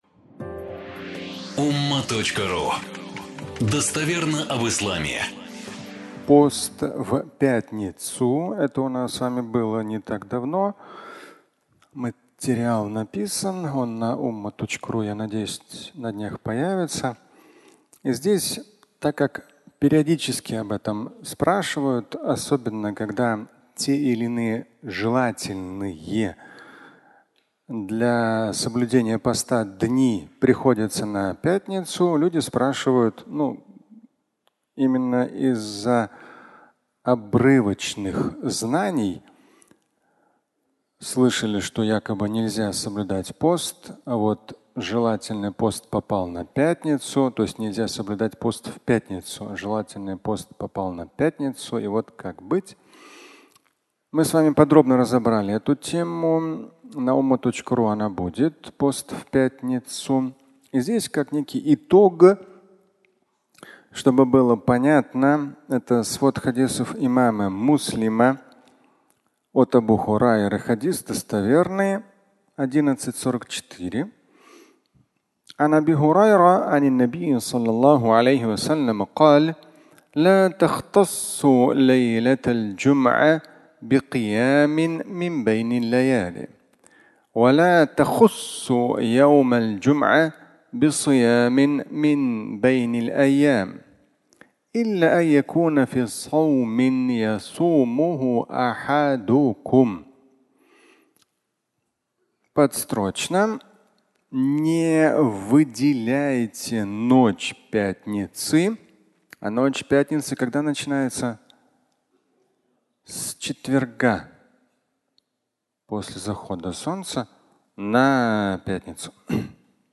Пост в пятницу (аудиолекция)